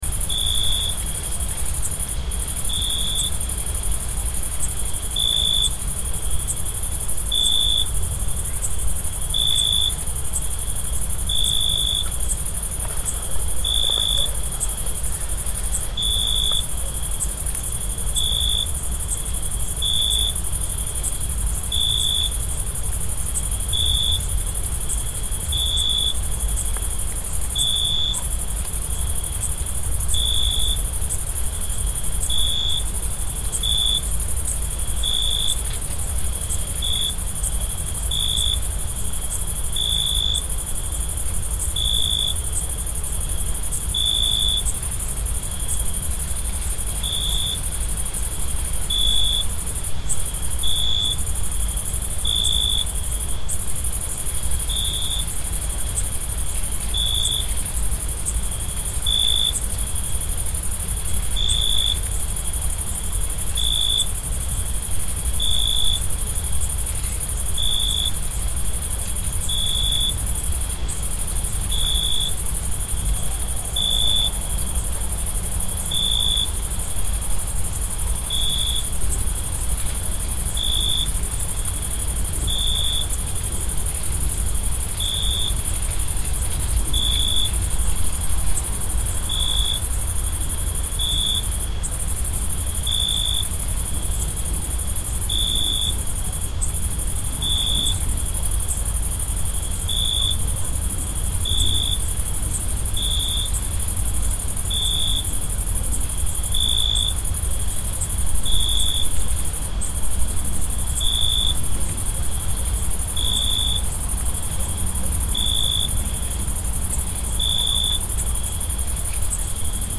Relaxing Sounds / Sound Effects 12 Feb, 2026 Summer Night Nature Ambience Sound Effect – Cicadas and Crickets Read more & Download...
Summer-night-nature-ambience-sound-effect-cicadas-and-crickets.mp3